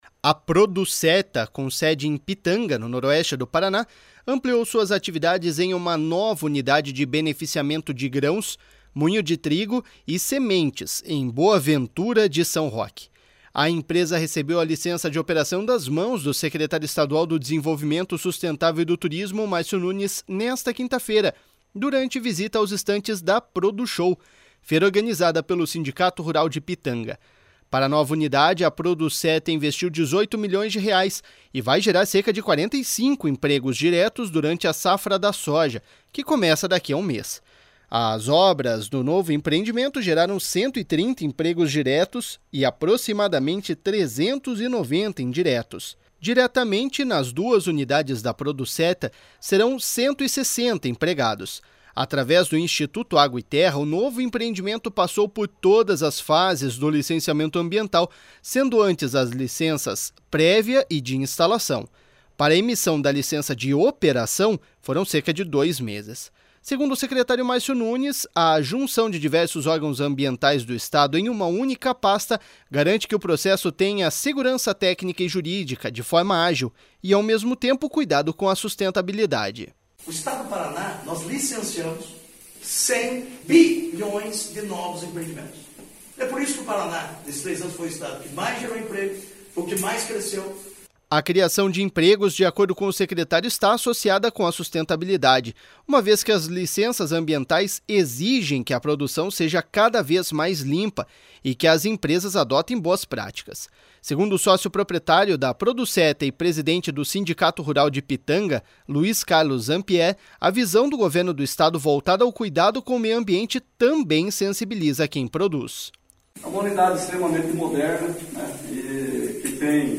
Segundo o secretário Márcio Nunes, a junção de diversos órgãos ambientais do Estado em uma única pasta garante que o processo tenha segurança técnica e jurídica de forma ágil e, ao mesmo tempo, o cuidado com a sustentabilidade.// SONORA MÁRCIO NUNES.//